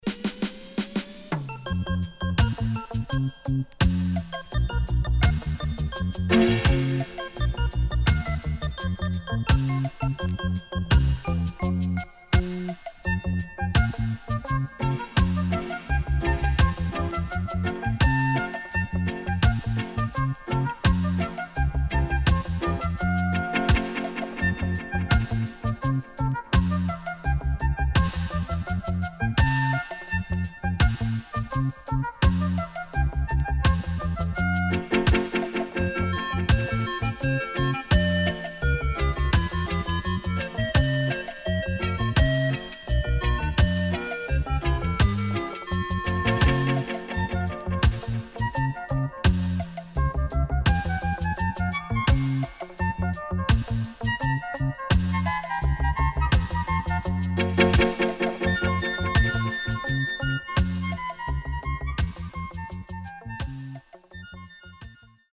【12inch】
SIDE A:盤質は良好です。